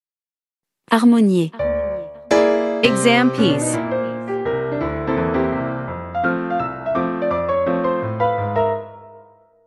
Sample MP3 | Bars : 24b-28
Professional-level Piano Exam Practice Materials.
• Vocal metronome and beats counting